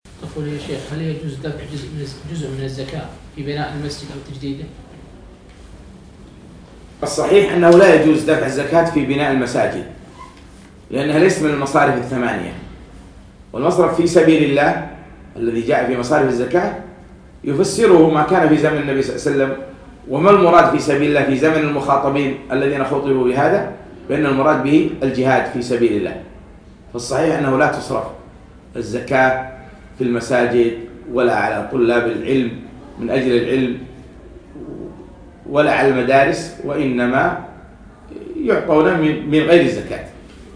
مقتطف من محاضرة فوائد وعبر من سورة الكهف المقامة في مركز إلهام البوشي بتاريخ 3 4 2017